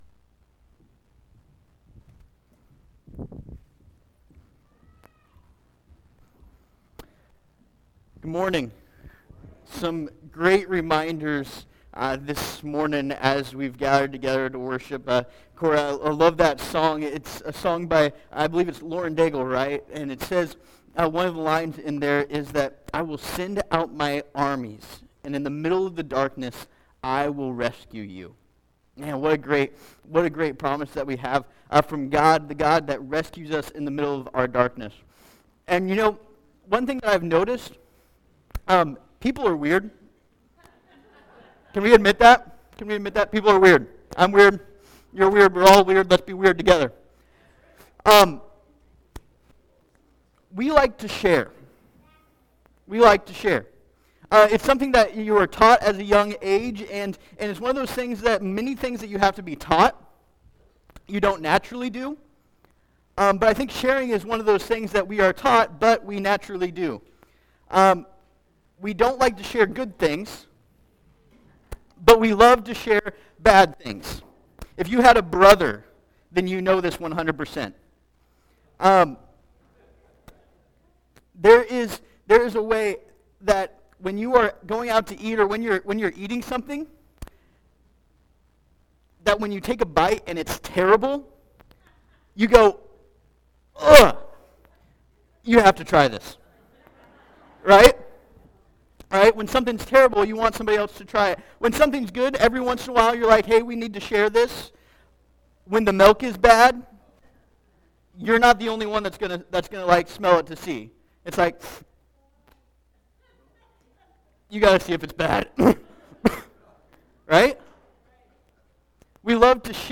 Gospel In Galatians Passage: Galatians 6 Service Type: Sunday Morning Topics